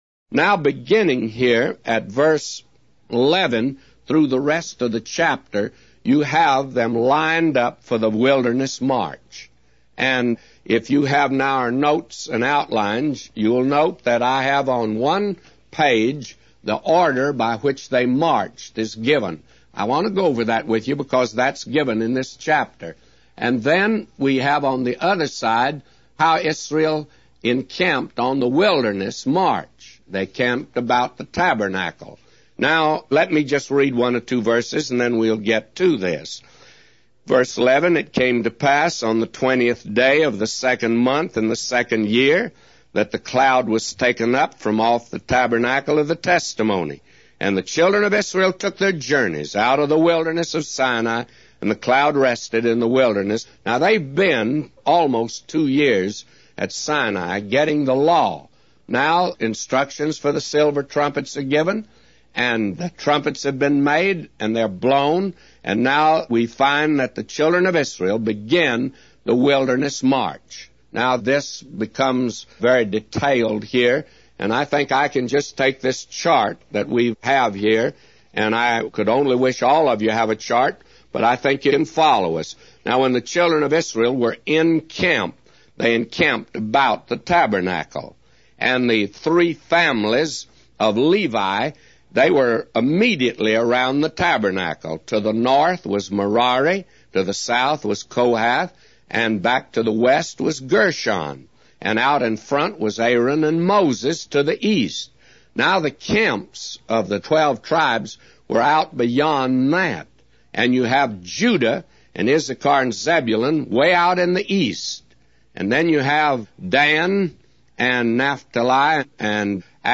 A Commentary By J Vernon MCgee For Numbers 10:1-999